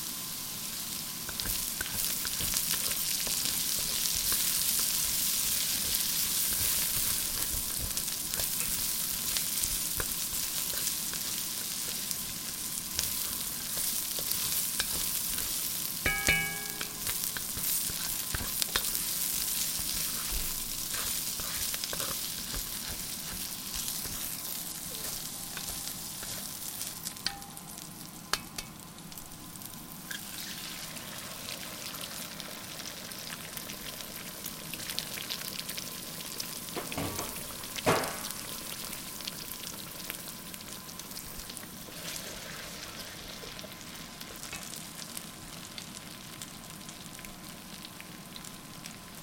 SFX炒菜05(Stir fry 05)音效下载